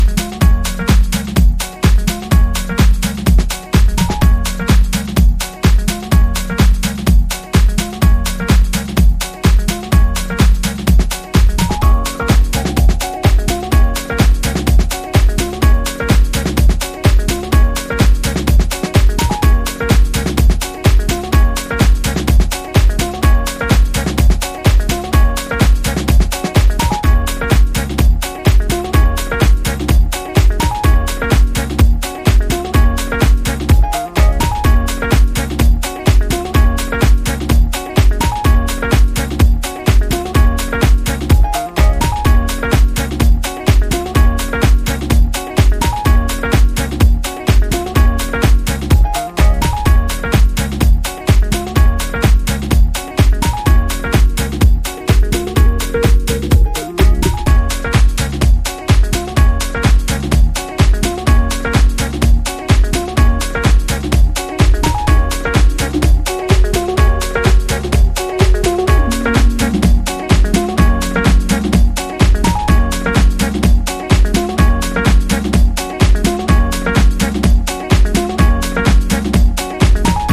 jackin' house